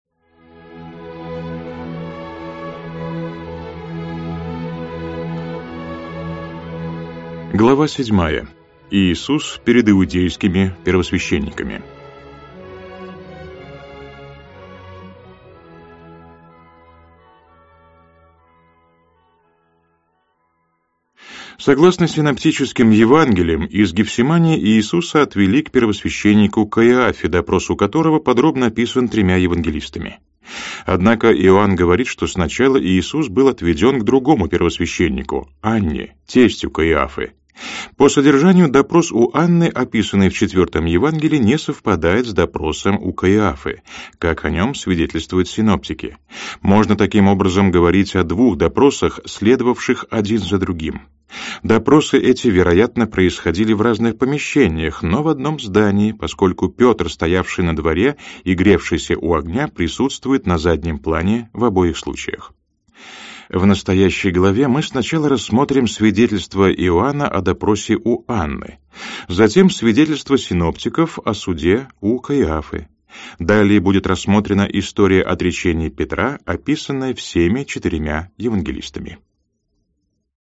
Аудиокнига Иисус Христос. Жизнь и учение. Книга VI. Смерть и Воскресение. Том 4. Глава 7 Иисус перед иудейскими первосвященниками, Глава 8 Суд Пилата | Библиотека аудиокниг